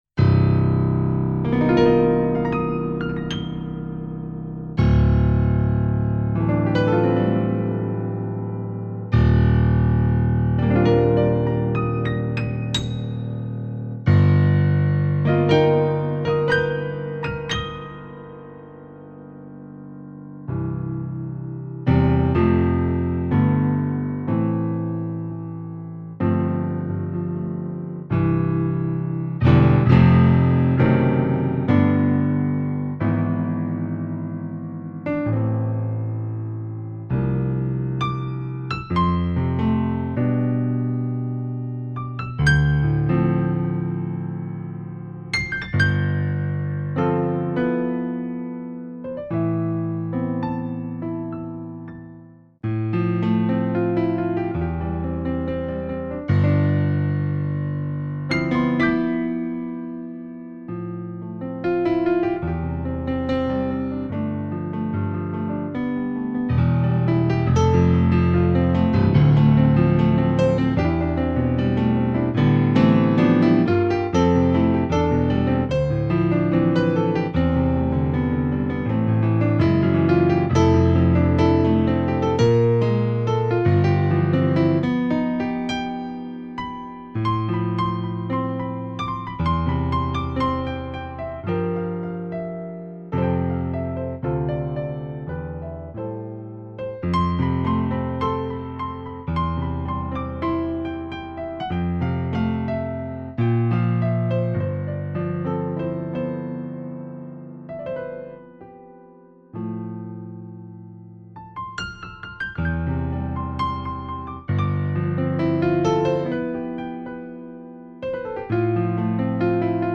German Grandサンプル